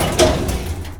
- Added SFX for opening and closing file cabinets, and an extra boing sound.
File Cabinet Open.wav